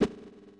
PlayerAttack.ogg